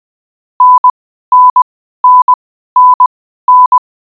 3. 電波警報符号部：モールス信号による電波警報符号5回（本botでは、N（安定）固定としています）
• 信号周波数：1000Hz
• WPM(Words per minute)：20WPM